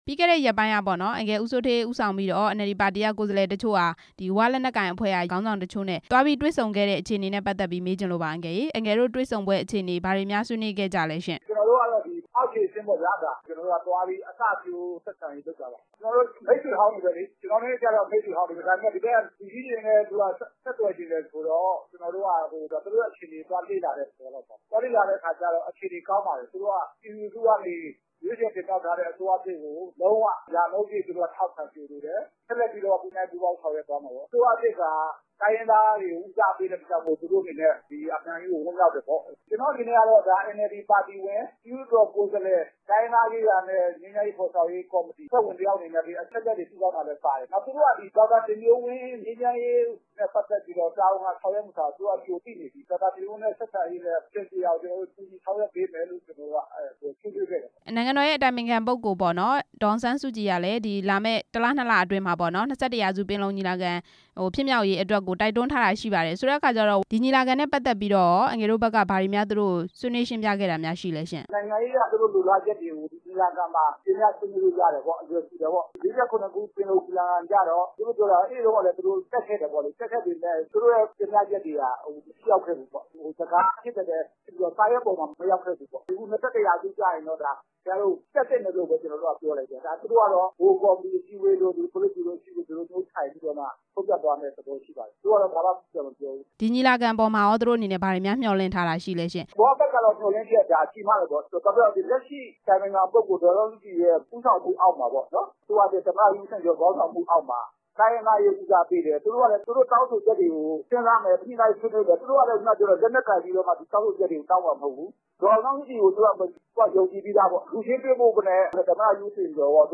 UWSP ခေါင်းဆောင်တွေနဲ့ ဆွေးနွေးမှု မေးမြန်းချက်